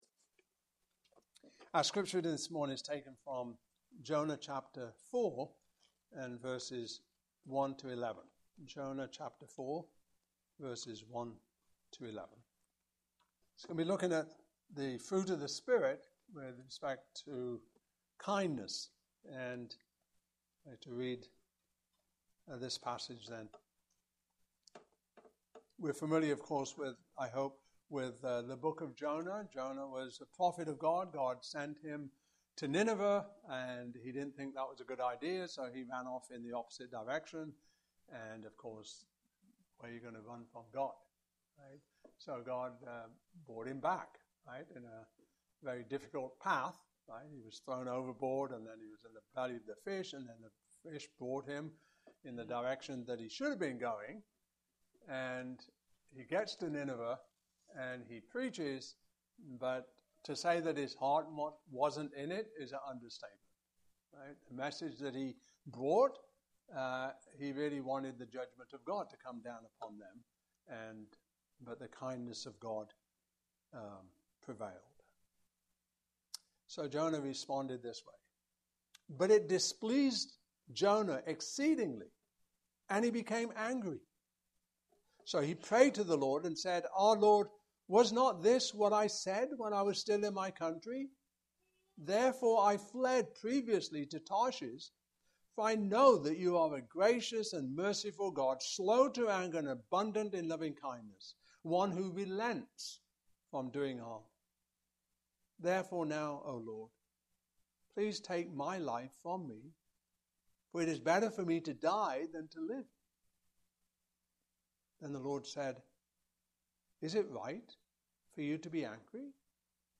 Passage: Jonah 4:1-11 Service Type: Morning Service Topics